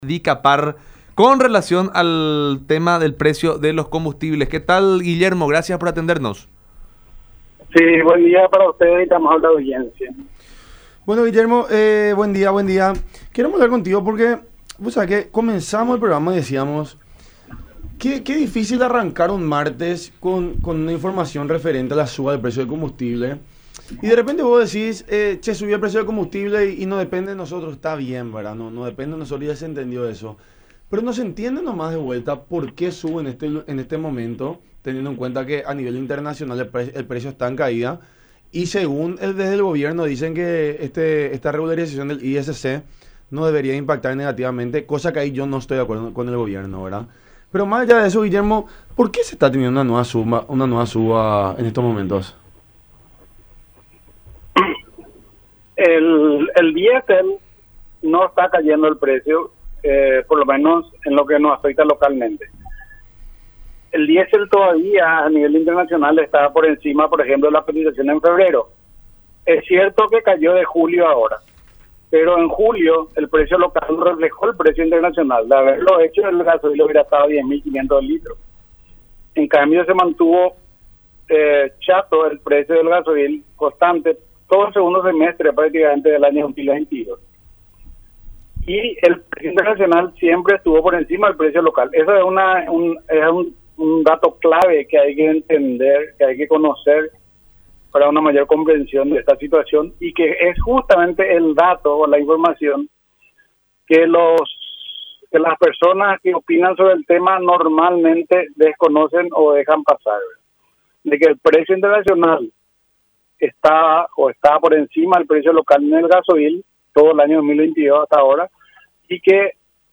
en charla con La Unión Hace La Fuerza por Unión TV y radio La Unión.